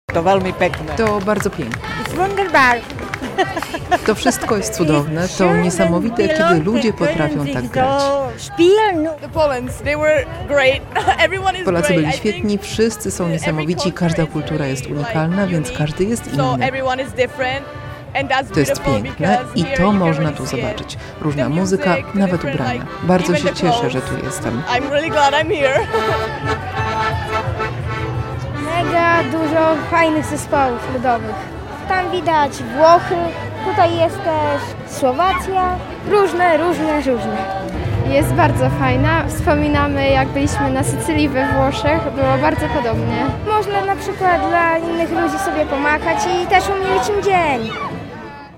Blisko 700 artystów przeszło ulicami słowackiego Kieżmarku. Wśród uczestników parady otwierającej Festiwal Europejskiego Rzemiosła Ludowego był też Ludowy Zespół Pieśni i Tańca Skowronki z Brańska.
relacja